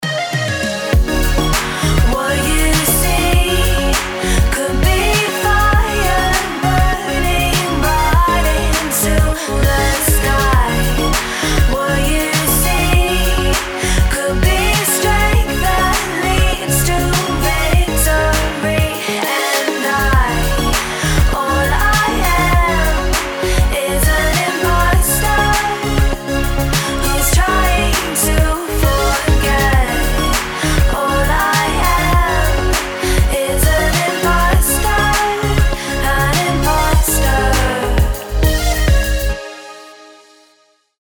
• Качество: 320, Stereo
Electronic
спокойные
tropical house